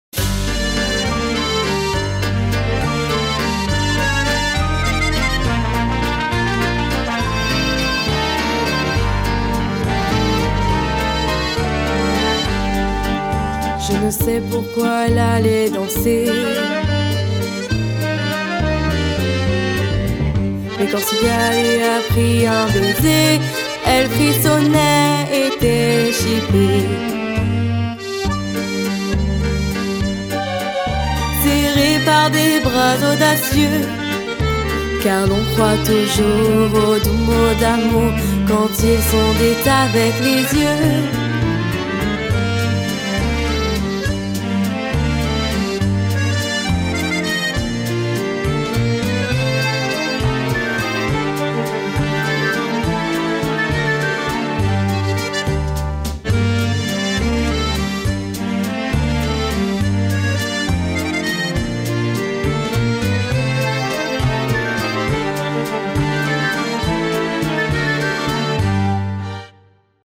La version chantée avec les trous (Expert)